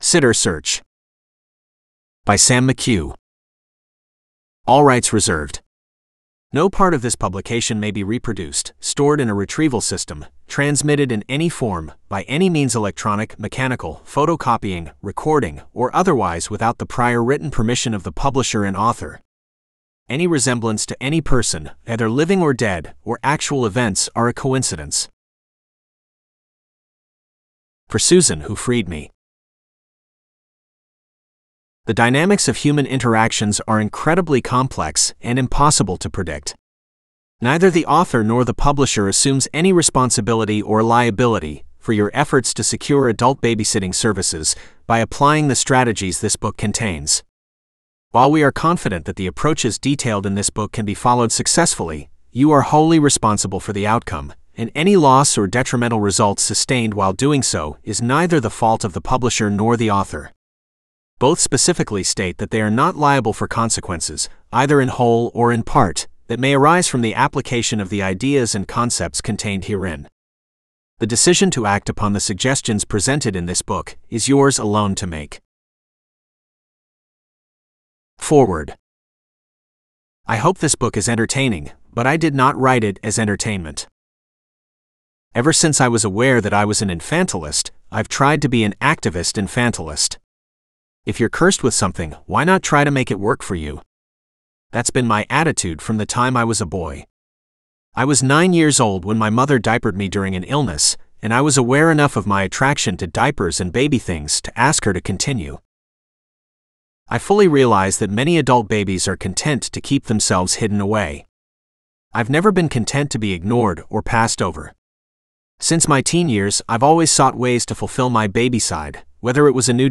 Sitter Search (AUDIOBOOK- female): $US6.75